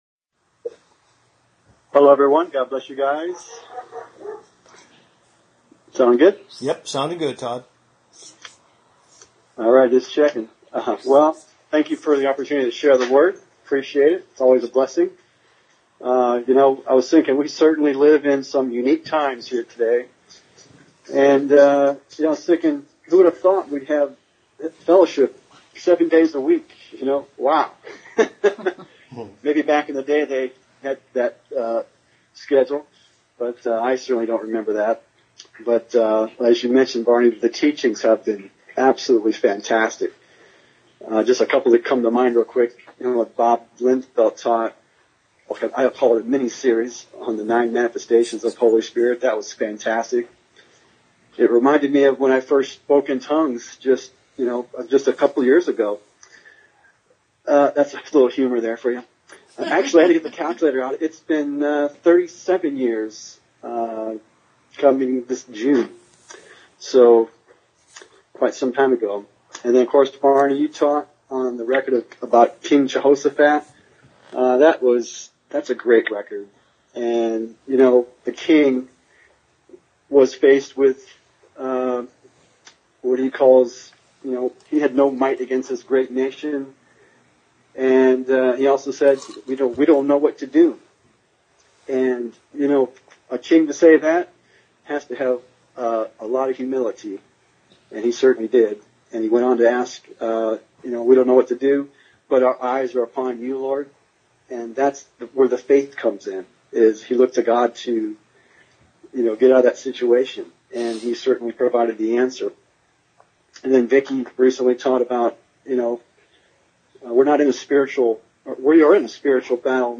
Conference Call Fellowship Date